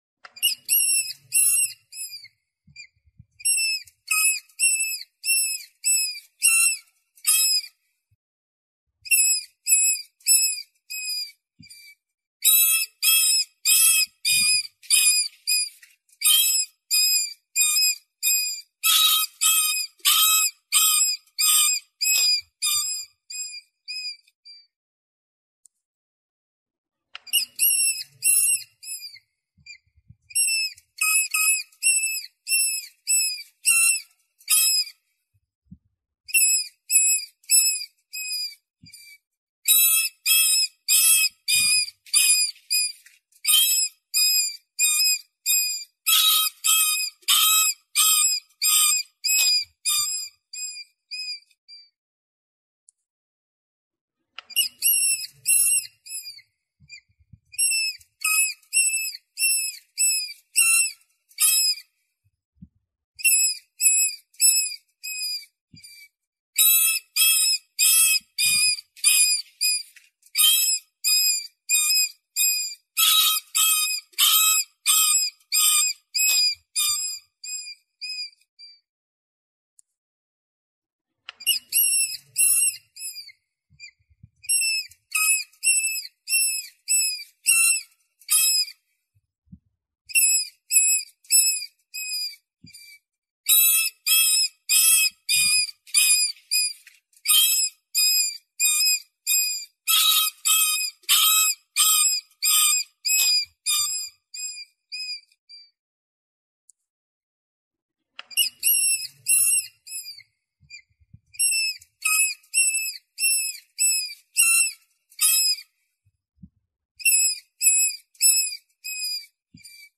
คุณสามารถดาวน์โหลดเสียงหนูพุกร้องหาคู่ (เข้าใวแน่นอน 100%) mp3 ได้ฟรี เสียงหนูพุก (หนูนา) ร้องหาคู่เข้ามาอย่างรวดเร็ว 100% และมีเสียงจริงและชัดเจน
เสียงหนูพุกร้องหาคู่
หมวดหมู่: เสียงสัตว์ป่า